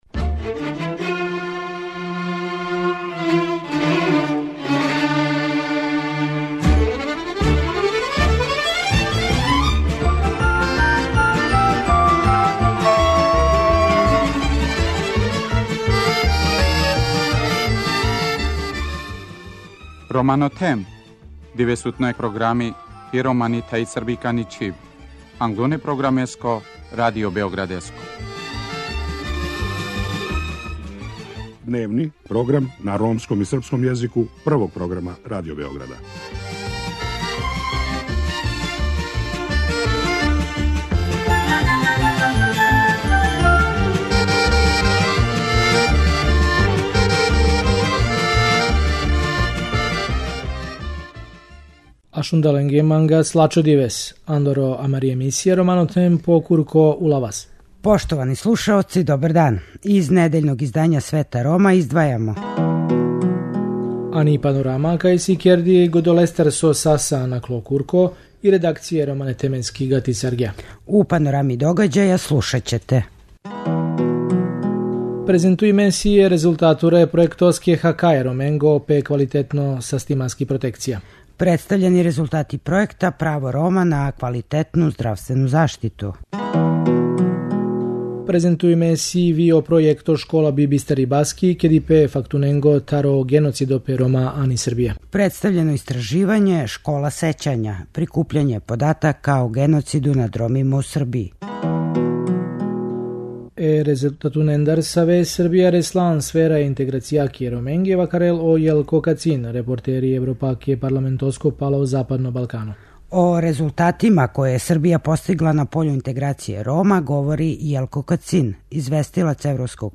О резултатима које је Србија постигла на пољу интеграције Рома говори Јелко Кацин известилац Европског Парламента за западни Балкан.